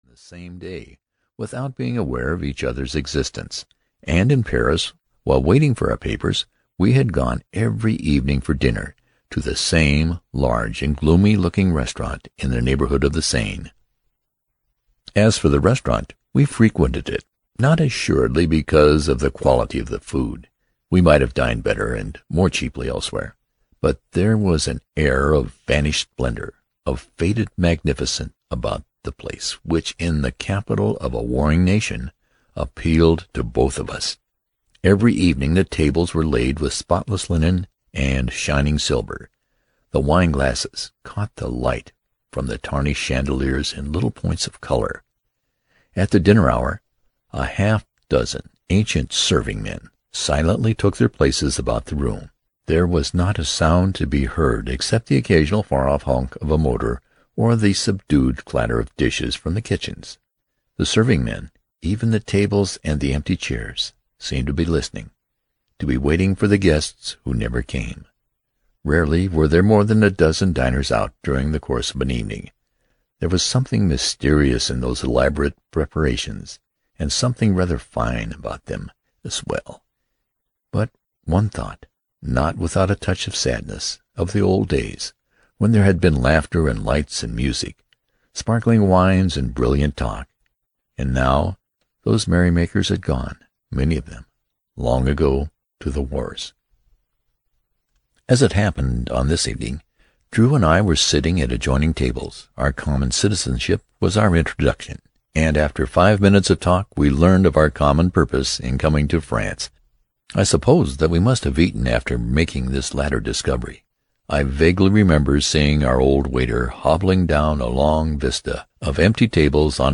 High Adventure (EN) audiokniha
Ukázka z knihy